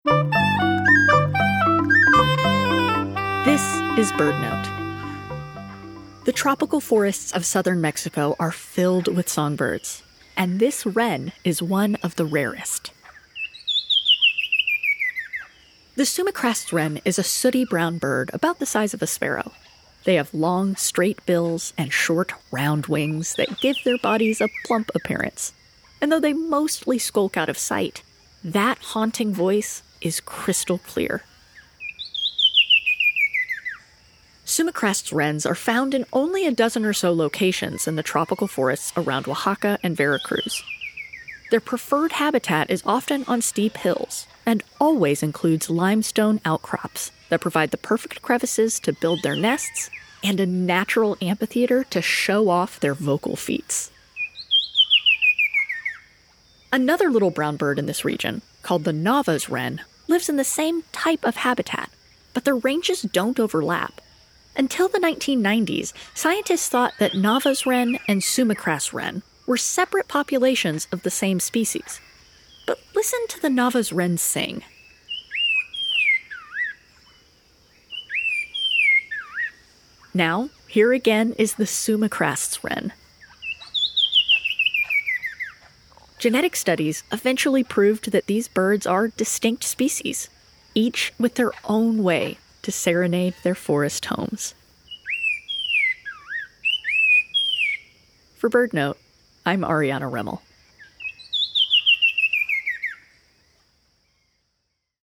Sumichrast’s Wren and Nava’s Wren are both sooty-brown songbirds of southern Mexico. They both live in tropical forests where limestone outcrops provide a natural amphitheatre to show off their vocal feats. Yet the two have very different songs and are never found in the same place.